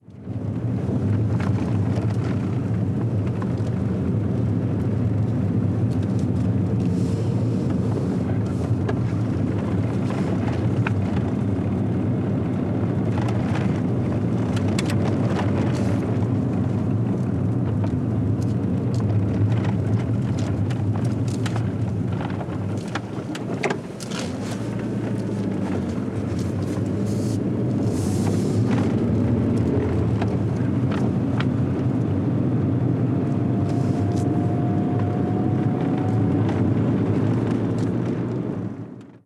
Motor normal desde el interior de un coche Golf
motor
Sonidos: Transportes